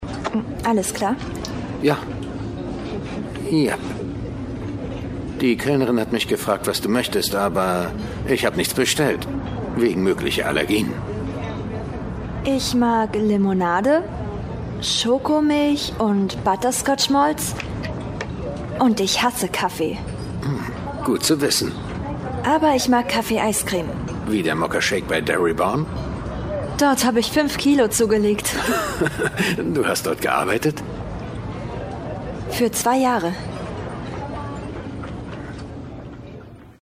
Werbung Butter B + MUSIK